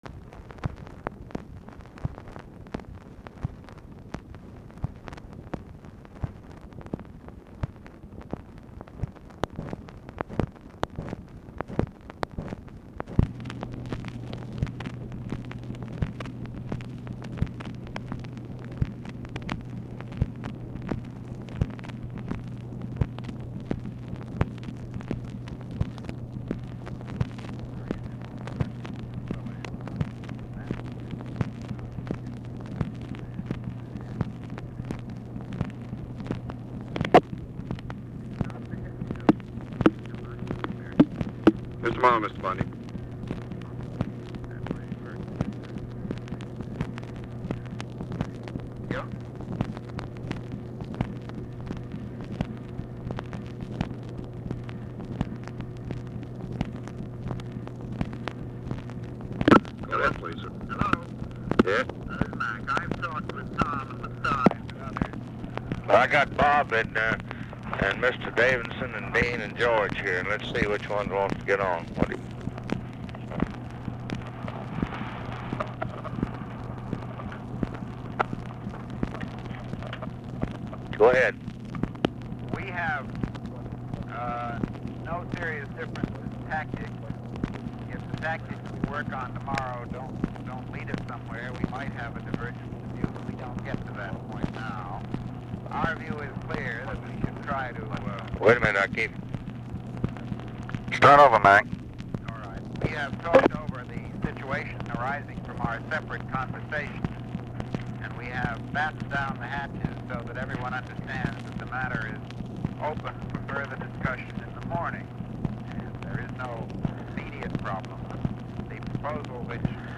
Telephone conversation
BUNDY IS IN DOMINICAN REPUBLIC, IS DIFFICULT TO HEAR, AND IS ON HOLD 1:00
BUNDY HAS BRIEF OFFICE CONVERSATION DURING CALL
Location Of Speaker 1 White House Situation Room, Washington, DC